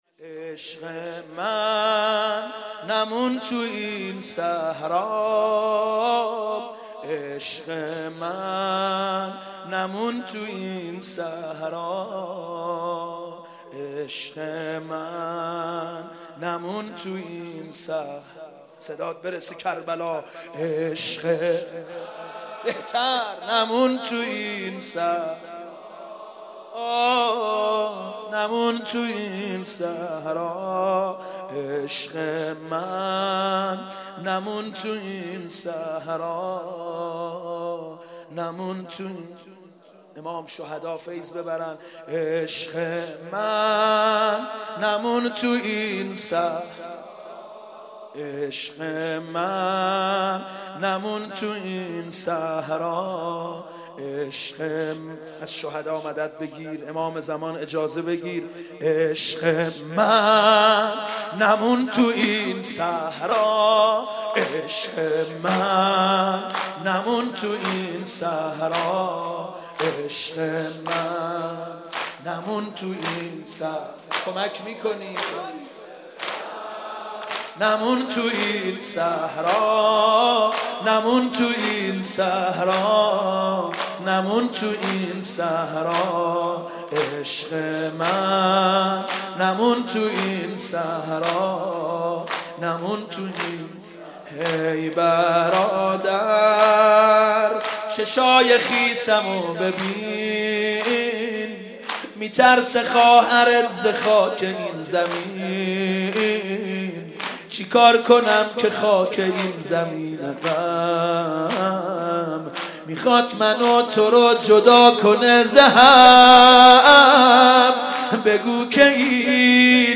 شب اول محرم 91 - هیئت رزمندگان قم